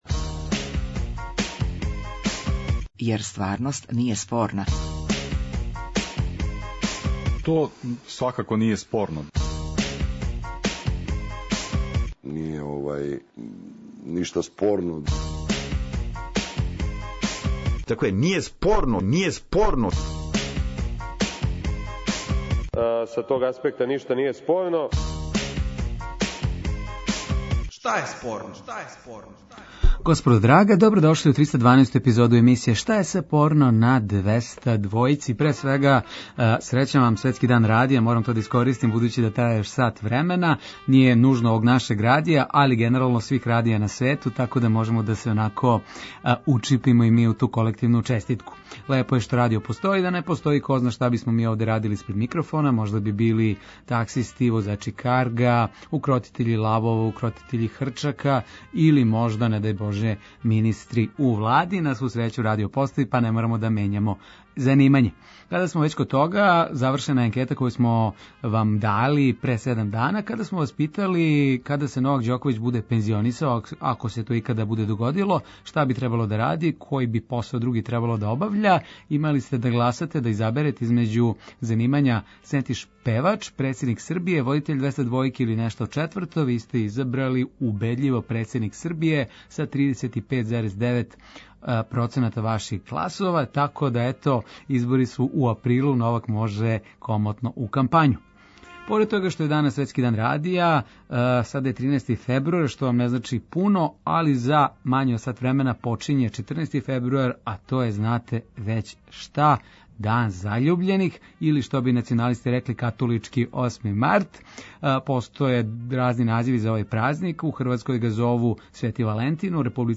Радијски актуелно - забавни кабаре интерактивног карактера